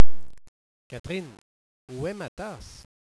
(le dialogue)